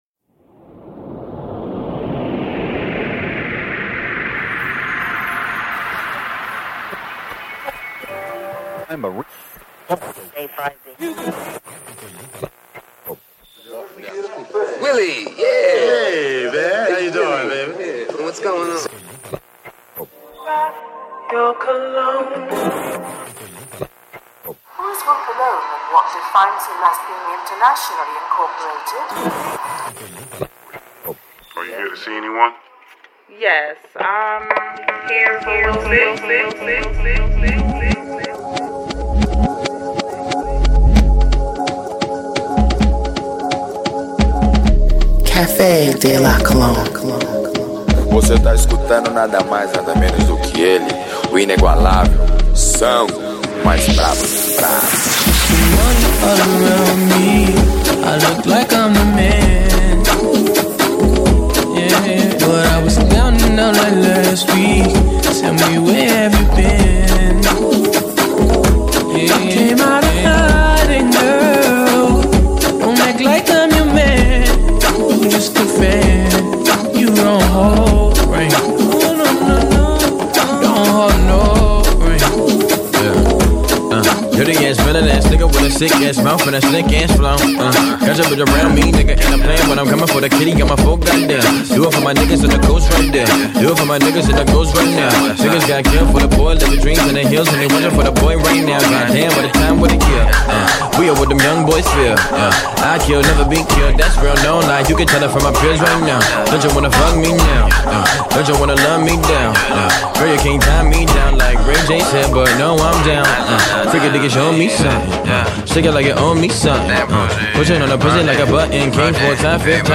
Exclusive designer music mixed